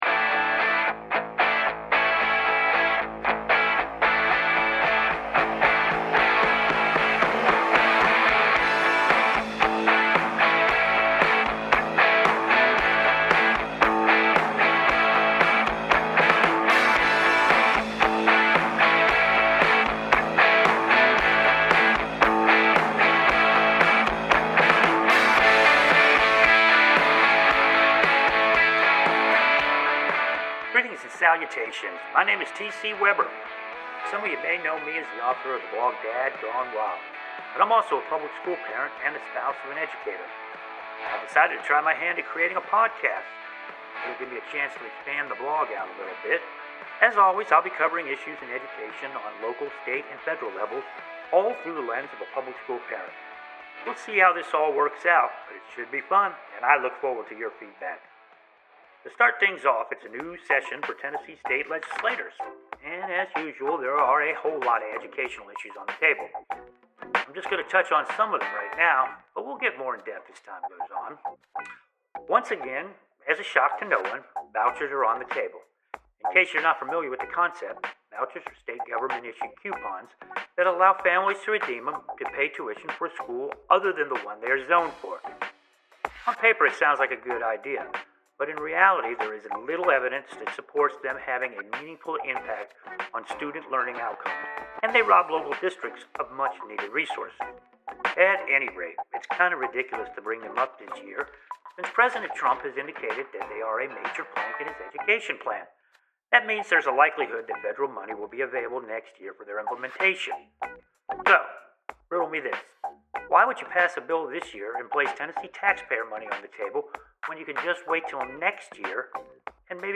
In this episode we'll look at the legislative docket for Tennessee and discuss some changes coming to Nashville schools. This first one is a little rough, but we are on our way!